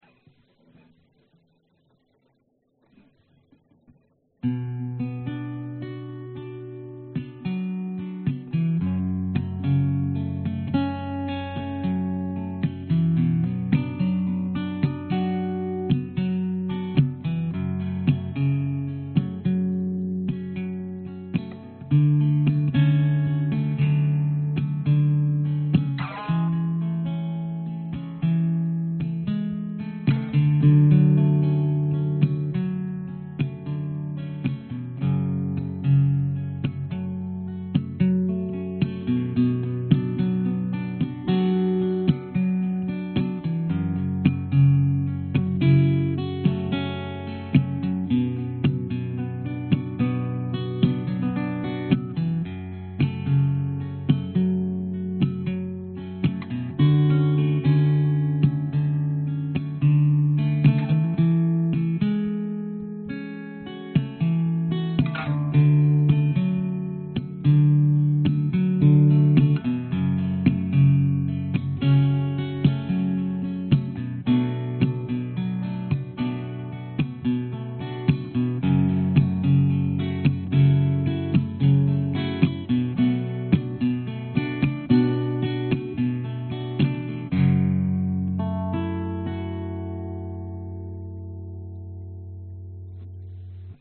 描述：原声GTR和弦，用2个通道录制，一个DI和一个电容话筒，加入iZotope合金+垃圾。
标签： 原声 寒冷 吉他
声道立体声